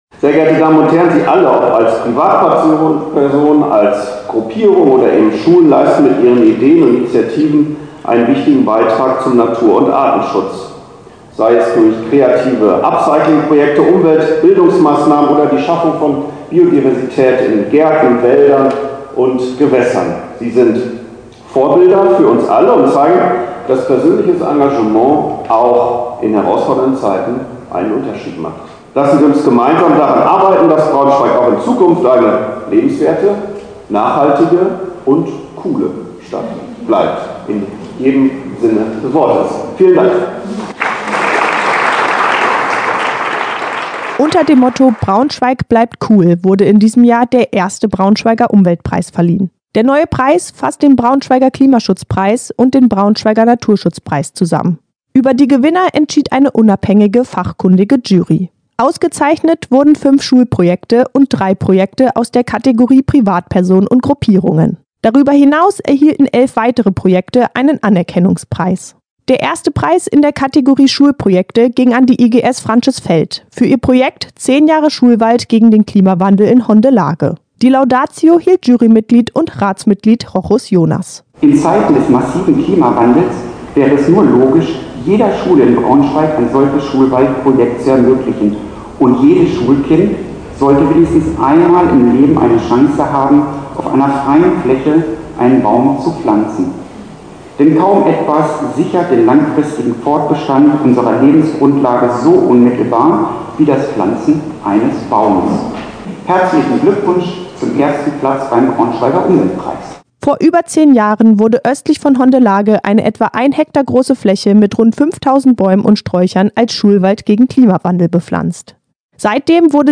Zum Auftakt sprach Oberbürgermeister Thorsten Kornblum.